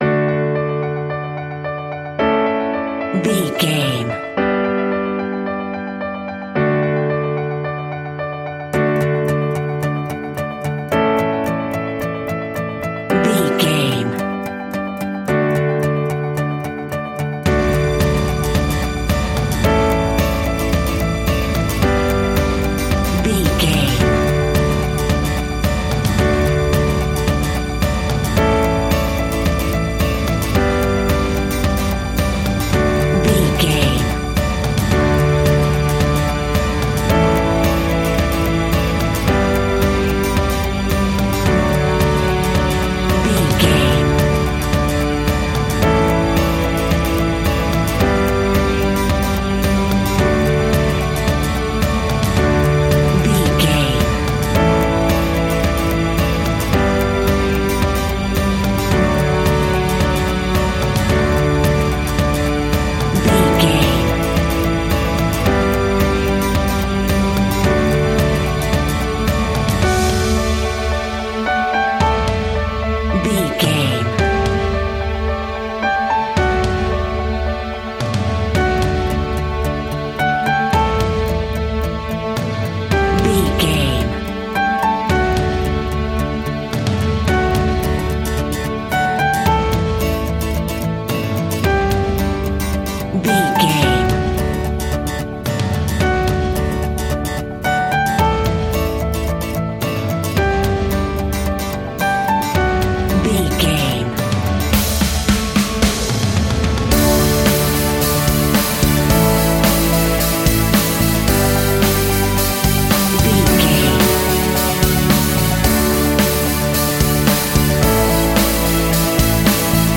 Ionian/Major
D
pop rock
indie pop
energetic
uplifting
catchy
upbeat
acoustic guitar
electric guitar
drums
piano
organ
bass guitar